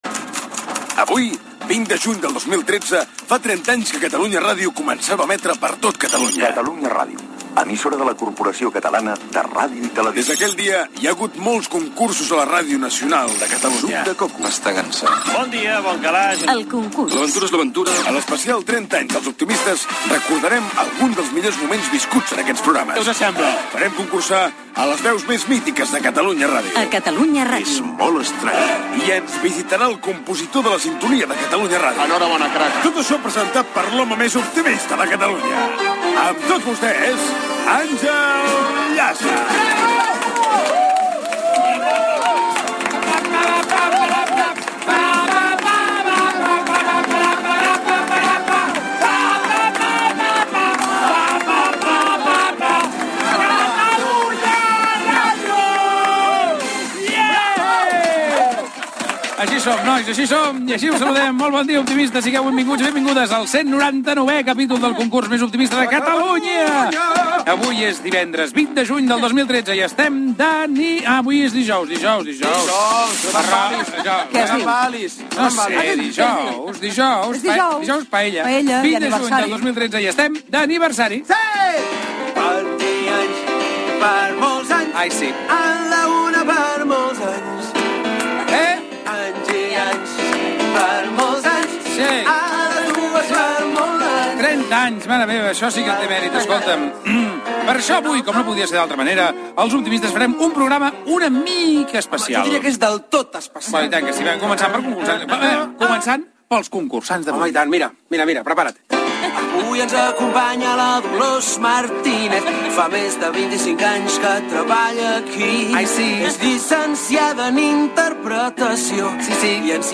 Cançó "Per molts anys".
Intervenció d'una oïdora del concurs.
Gènere radiofònic Entreteniment